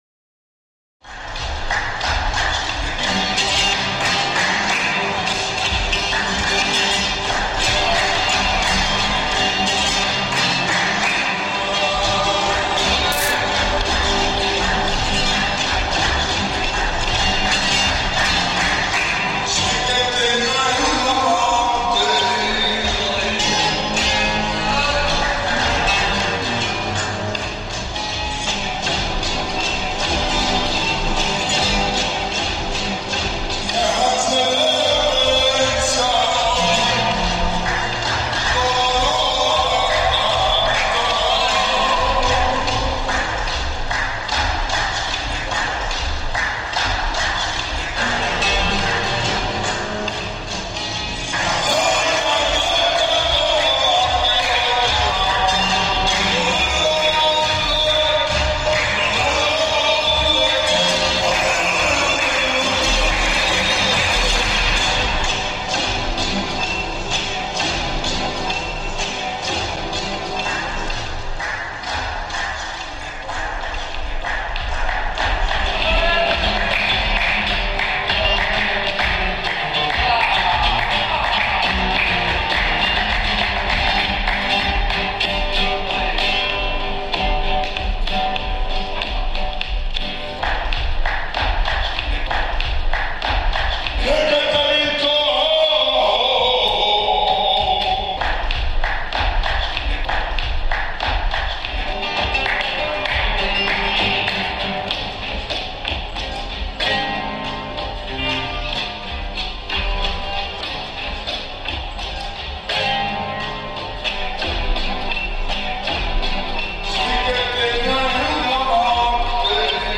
traditional flamenco dance